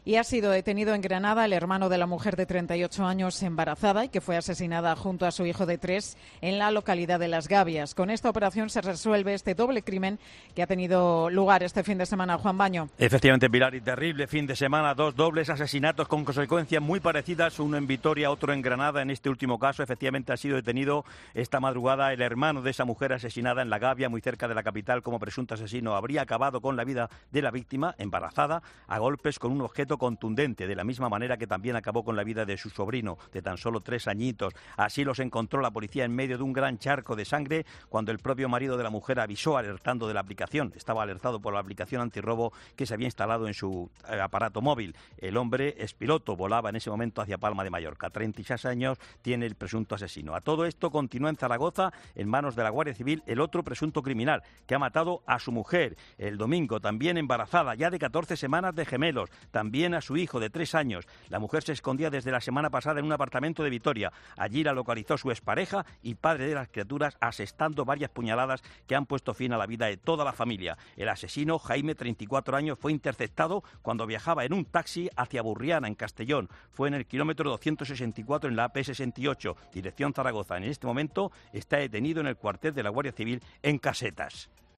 informa de los crímenes acontecidos en Vitoria y Las Gabias